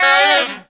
FUNKY FALL.wav